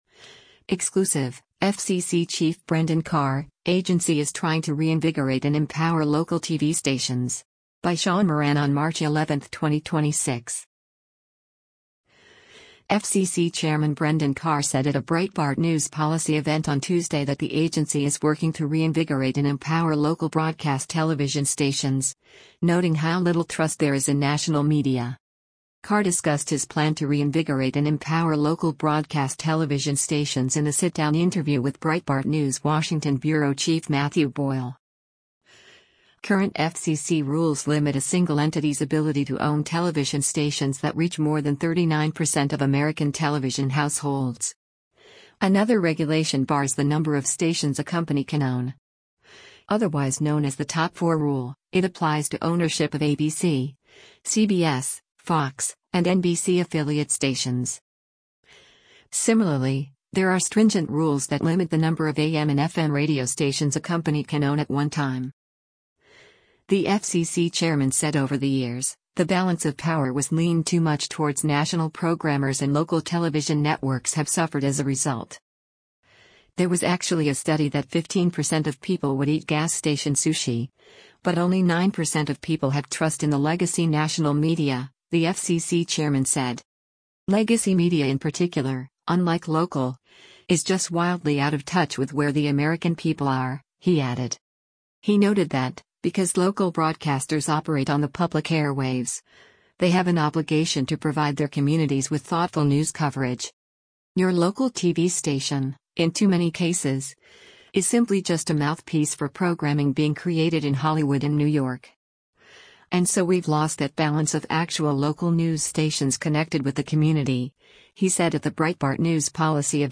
FCC Chairman Brendan Carr said at a Breitbart News policy event on Tuesday that the agency is working to “reinvigorate and empower” local broadcast television stations, noting how little trust there is in national media.